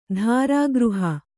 ♪ dhārāgřha